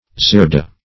Zerda \Zer"da\